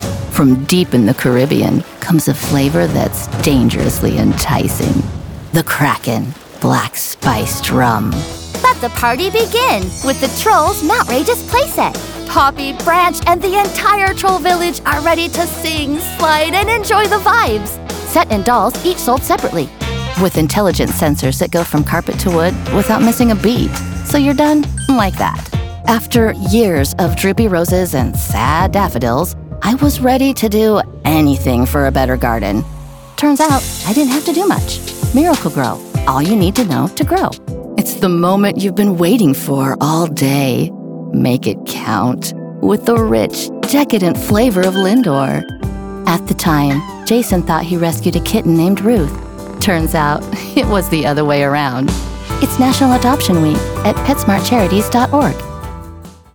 I have a warm, conversational style - a real girl next door
Commercial demo 2024